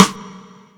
taiko-soft-hitwhistle.wav